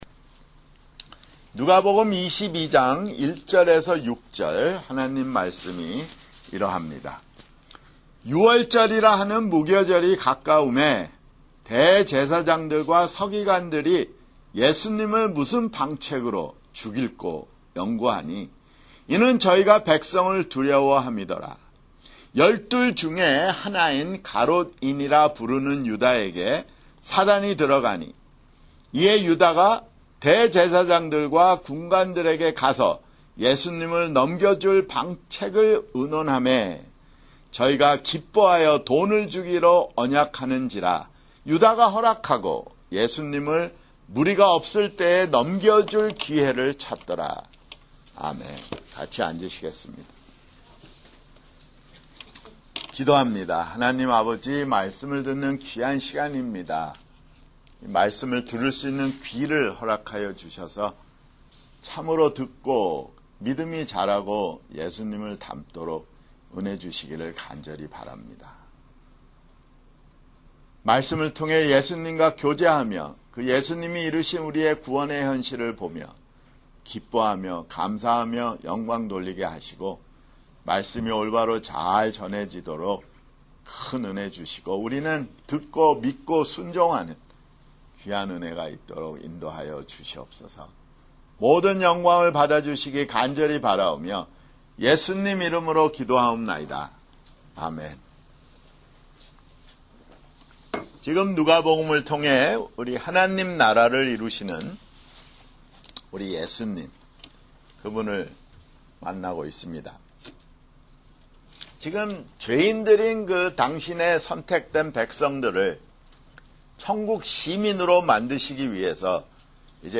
[주일설교] 누가복음 (147)